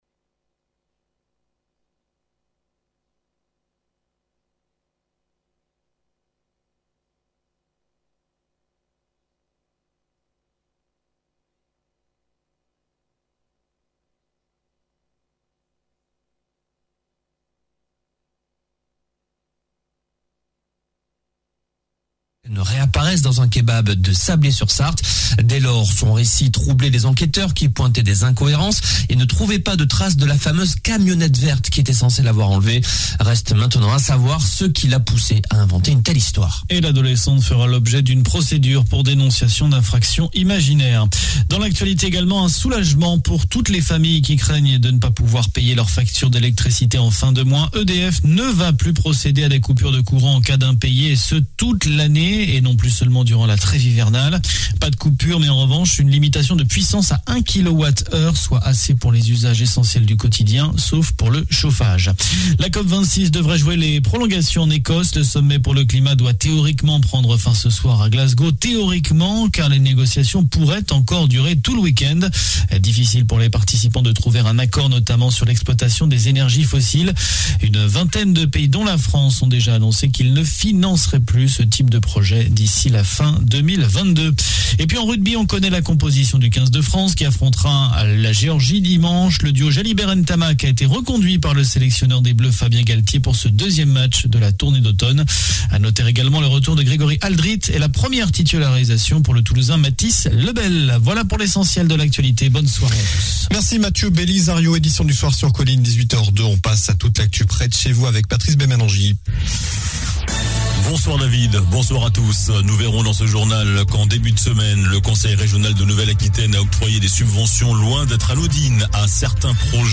Journal du vendredi 12 Novembre (soir)